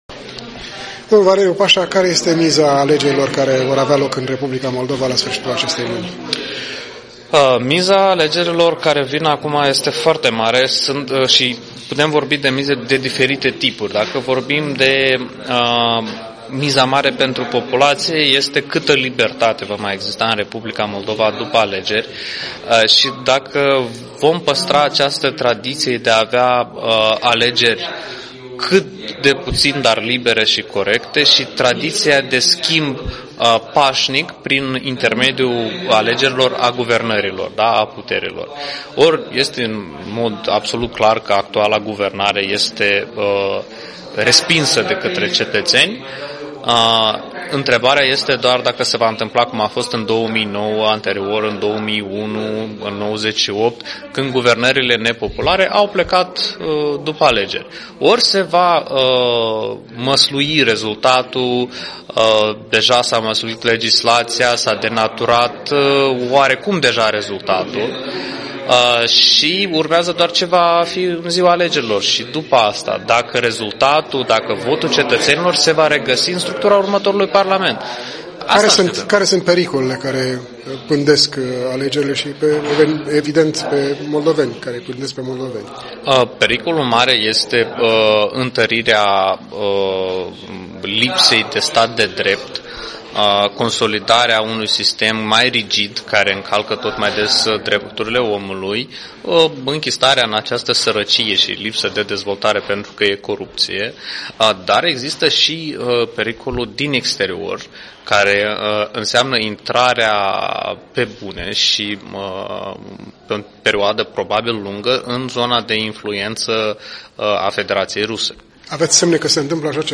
Un interviu cu expertul WatchDog.MD